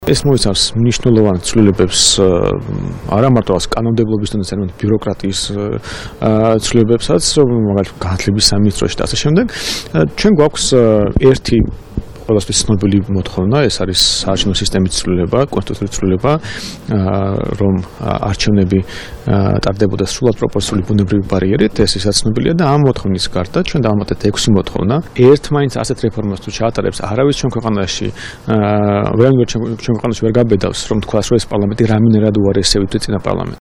მოისმინეთ “გირჩიდან”ალექსანდრე რაქვიაშვილის კომენტარი